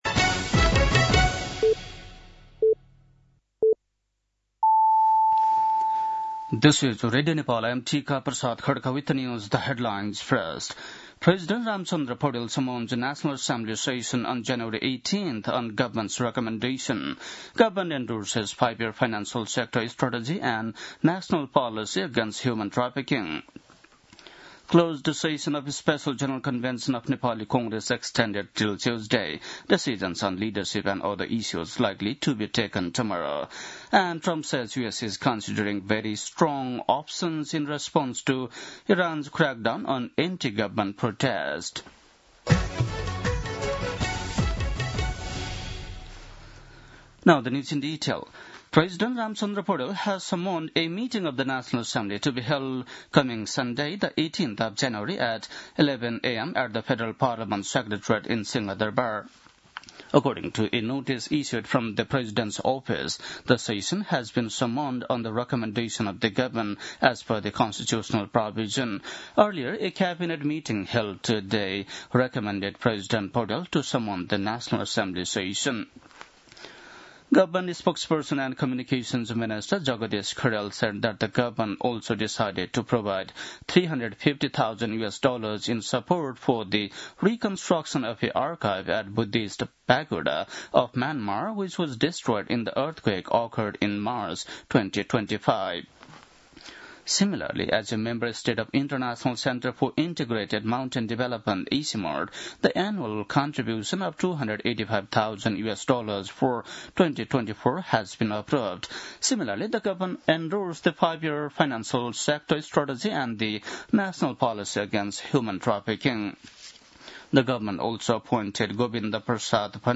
बेलुकी ८ बजेको अङ्ग्रेजी समाचार : २८ पुष , २०८२
8-pm-english-news-9-28.mp3